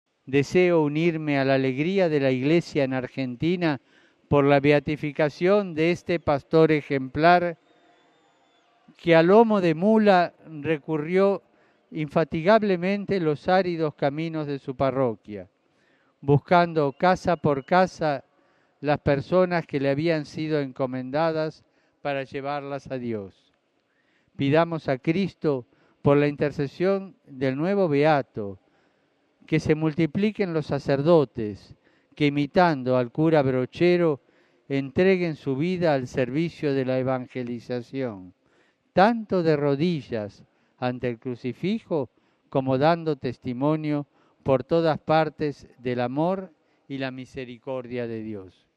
En sus saludos, hablando en nuestro idioma, el Papa Bergoglio recordó la beatificación que tuvo lugar ayer en Argentina del Cura Brochero: